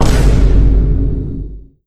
pause-retry-click.wav